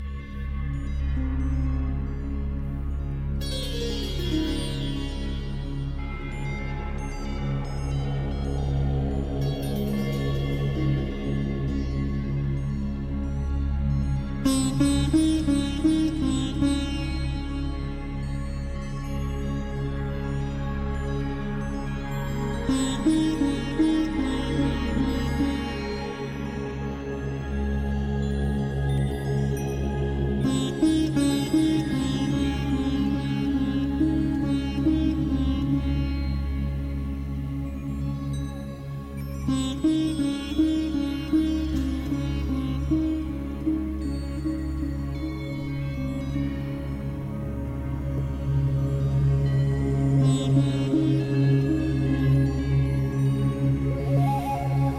Soittimet: sitar, huilu, jembee, keyboard, vocals & tanpura